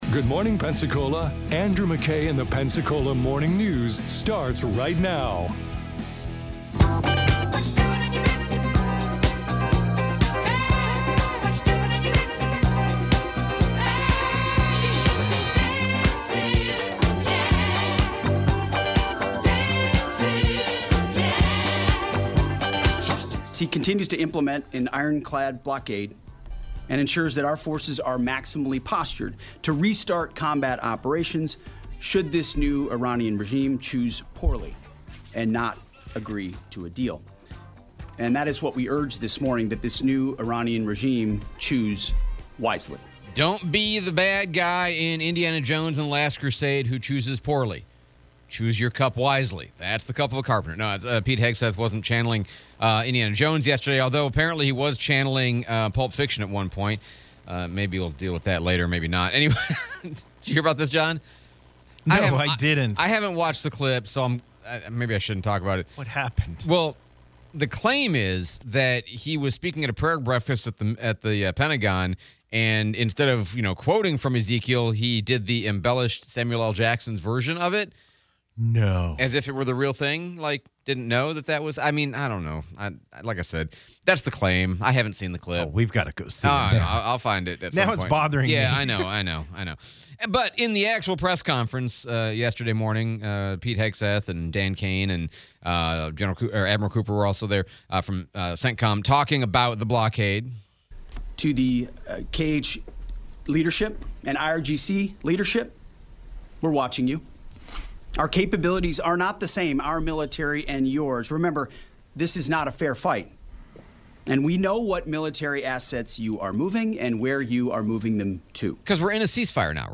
Dept. of War Press Conference